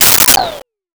Comical Pop And Swirl
Comical Pop and Swirl.wav